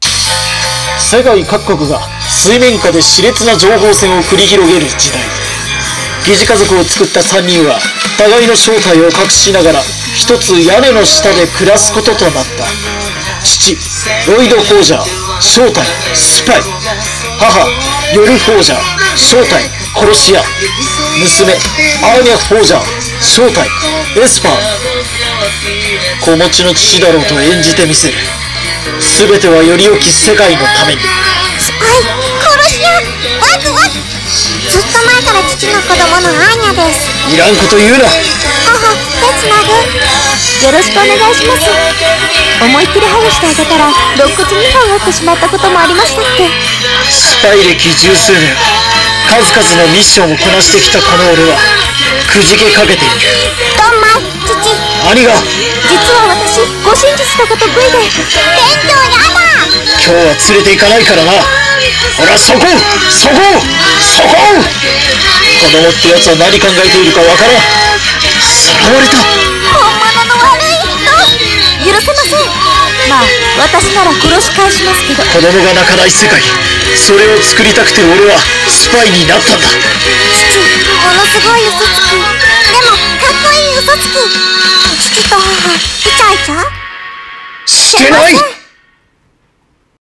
声劇アニメ「SPY×FAMILY」 本予告 コラボ歓迎投稿